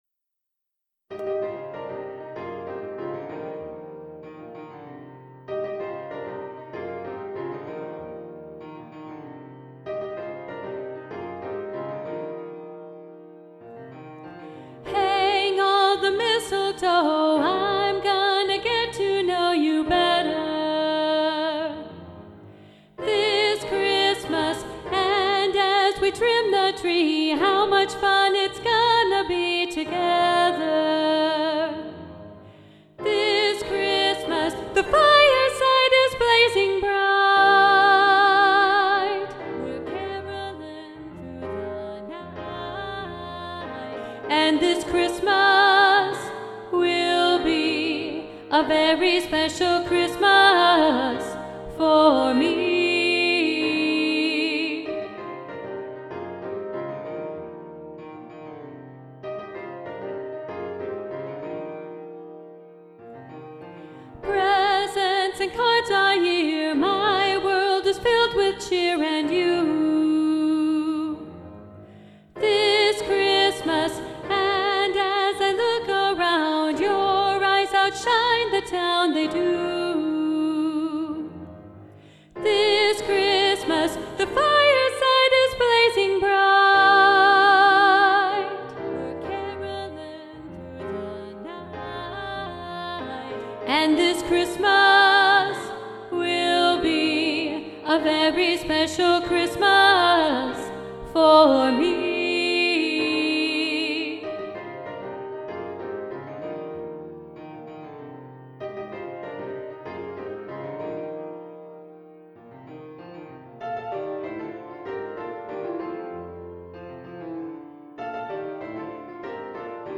This Christmas SSA – Soprano 2 Predominant – arr. Roger Emerson